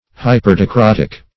Search Result for " hyperdicrotic" : The Collaborative International Dictionary of English v.0.48: Hyperdicrotic \Hy`per*di*crot"ic\, a. (Physiol.)